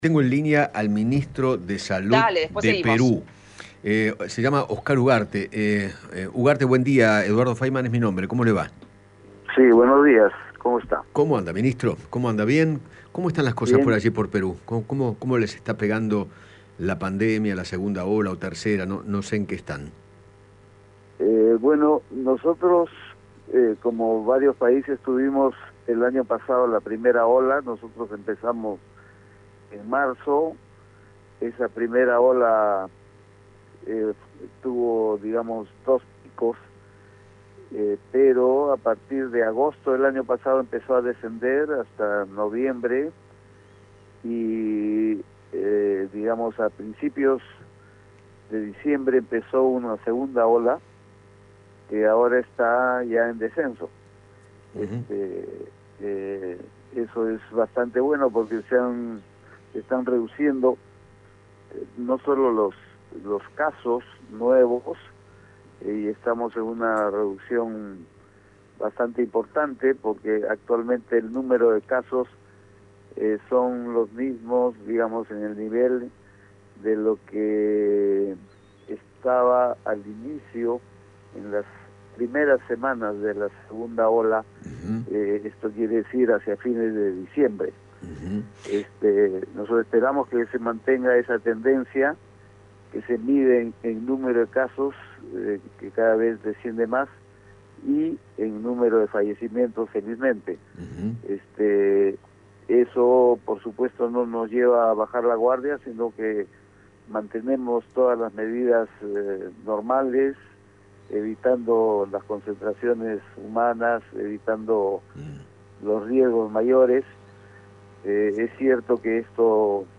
Oscar Ugarte, ministro de Salud de Perú, dialogó con Eduardo Feinmann sobre el plan de vacunación en aquel país y contó cuántas vacunas de Pfizer comenzarán a recibir a partir del mes que viene.